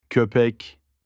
معنی اصلی و تلفظ “سگ” در ترکی استانبولی
dog-in-turkish.mp3